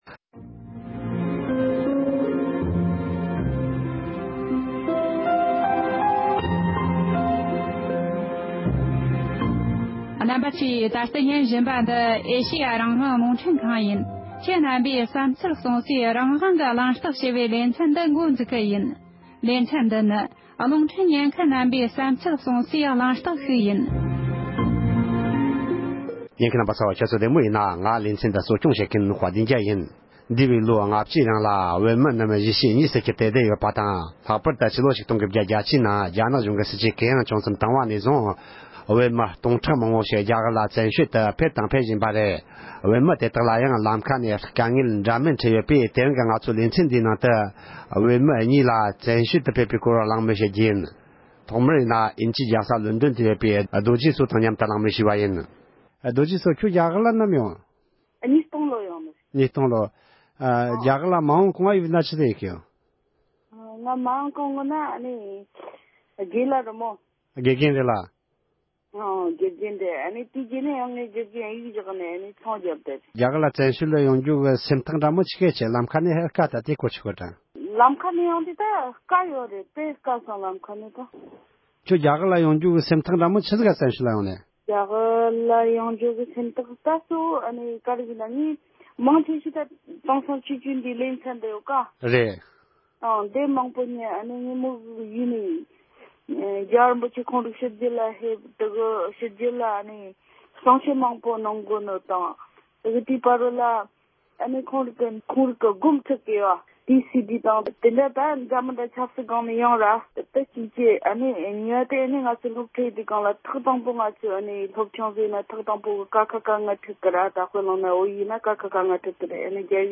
བཙན་བྱོལ་དུ་འབྱོར་ཡོད་པའི་བོད་མི་གཉིས་ཀྱིས་བཙན་བྱོལ་དུ་ཡོང་སྐབས་ཀྱི་མྱོང་ཚོར་འགྲེལ་བརྗོད་གནང་བ།